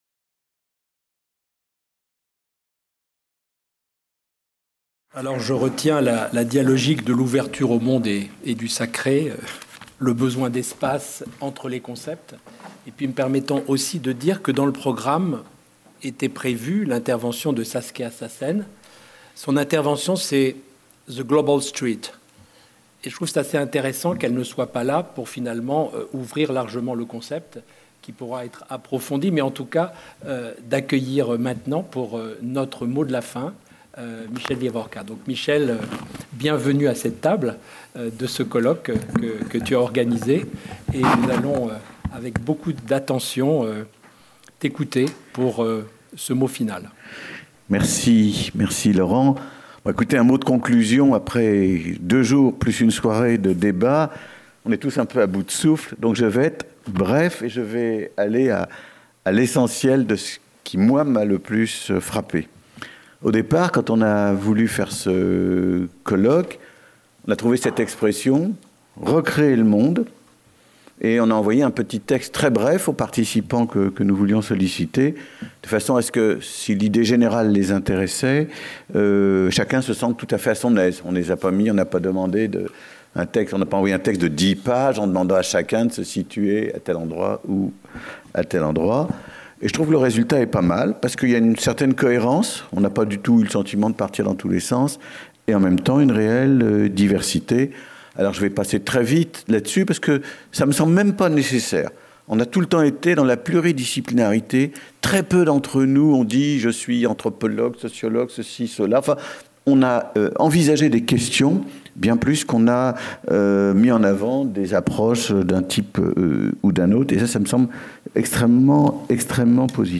Vendredi 20 mai - Philharmonie, Salle de conférence Clôture par Michel Wieviorka